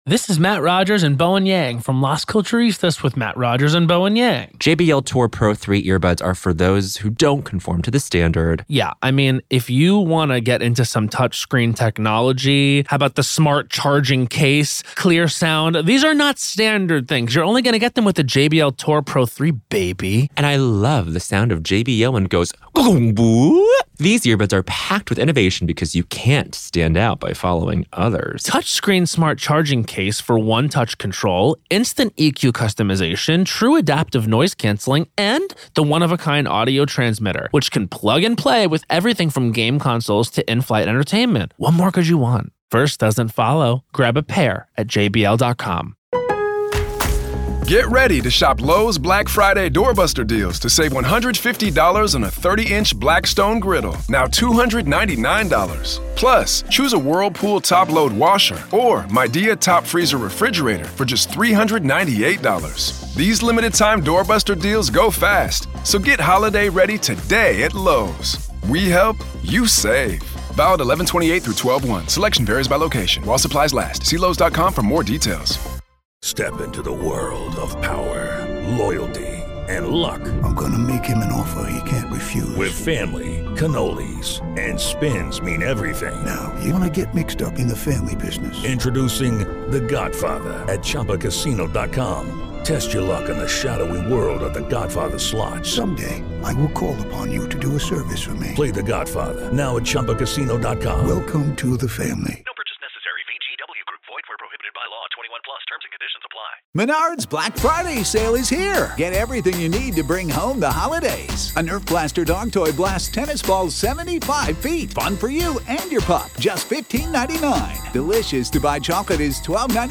The conversation dives deep into the psychology of incremental abnormality — how small behavioral shifts go unnoticed until the monster is fully formed.